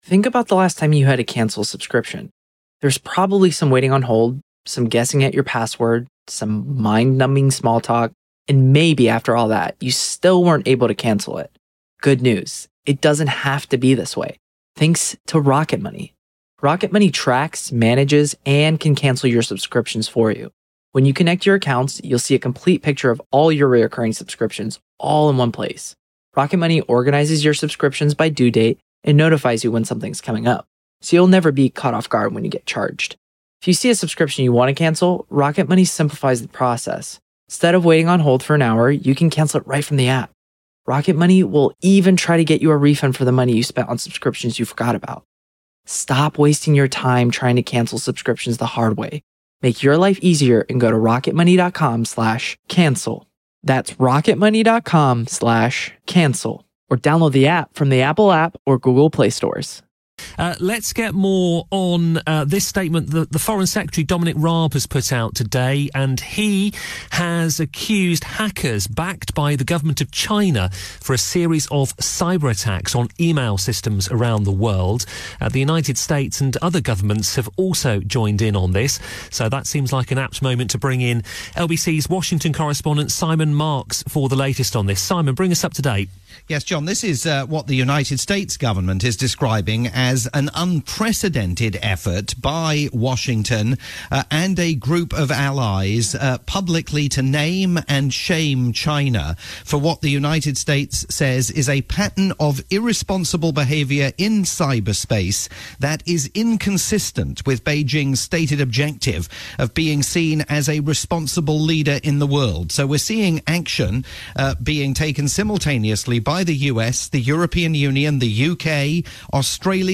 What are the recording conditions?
latest roundup for LBC News in the UK